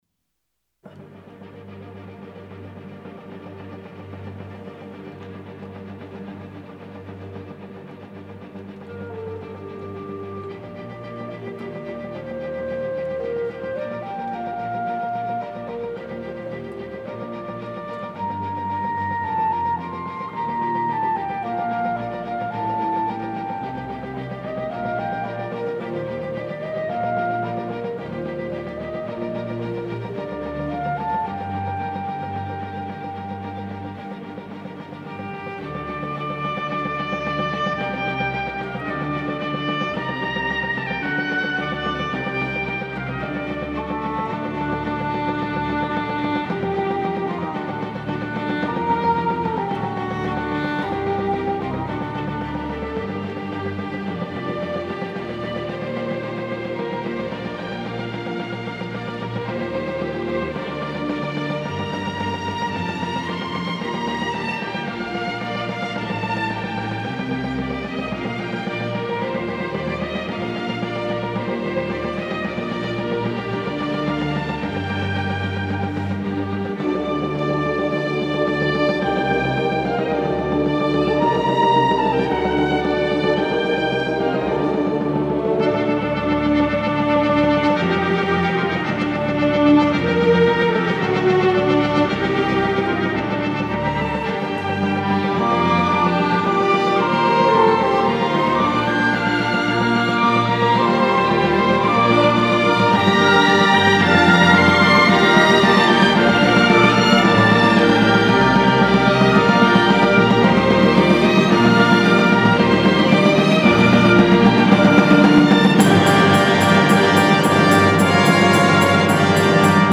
Хочешь, классическую музыку предложу под твоё фото?